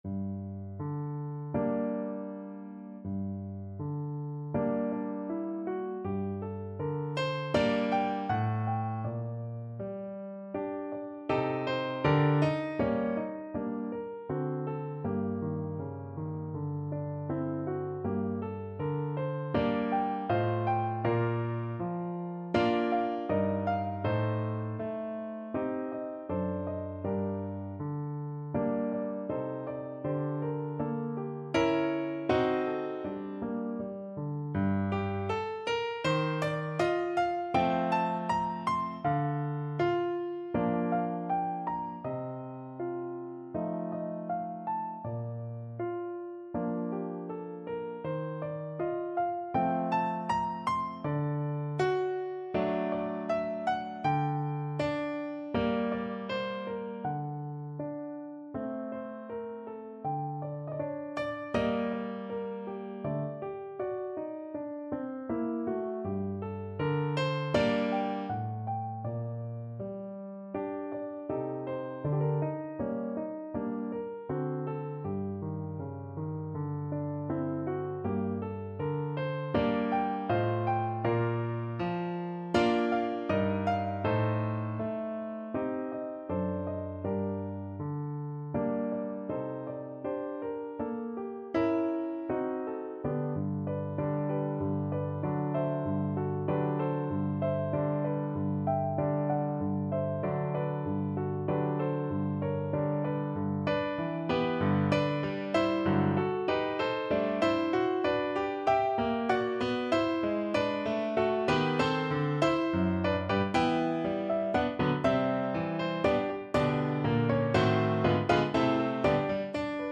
No parts available for this pieces as it is for solo piano.
4/4 (View more 4/4 Music)
Andante cantabile
Classical (View more Classical Piano Music)